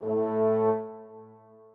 strings2_6.ogg